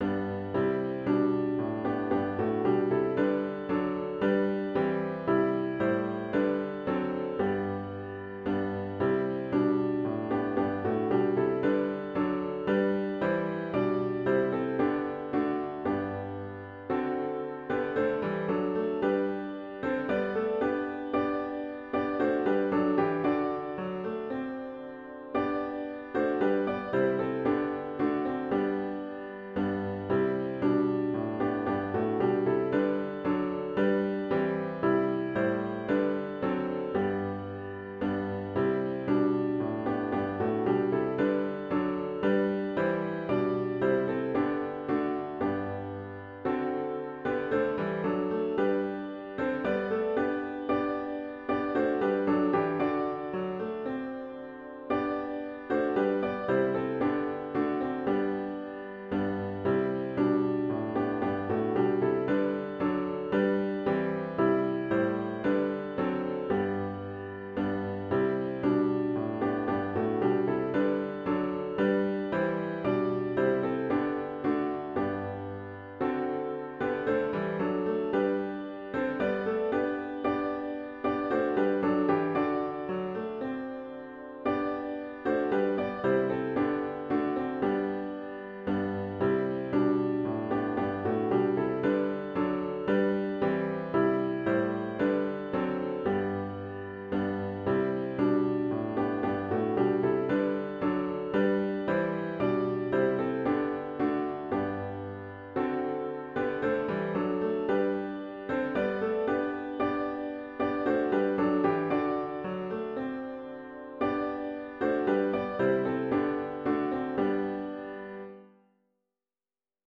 OPENING HYMN   “God of Grace and God of Glory”   GtG 307